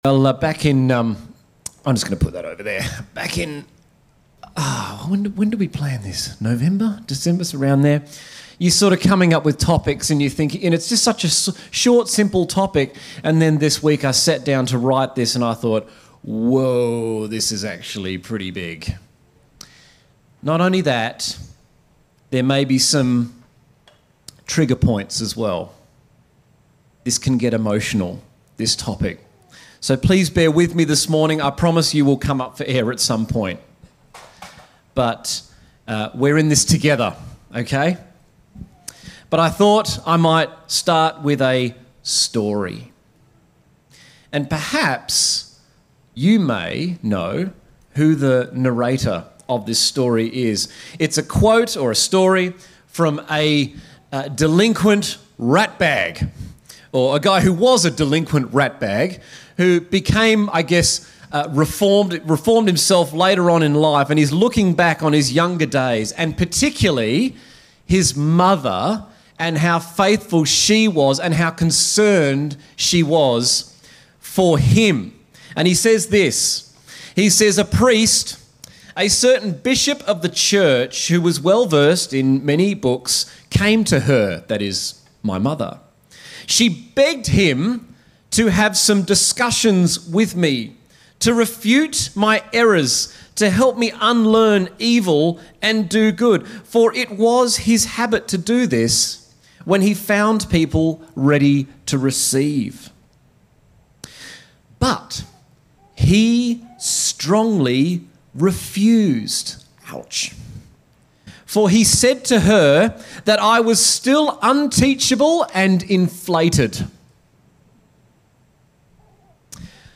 A message from the series "Time to Pray."